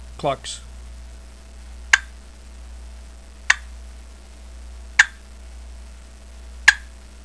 Listen to 7 seconds of clucks
• Produces extra loud, keen, raspy notes totally different from conventional boxes
southlandscreaminclucks7.wav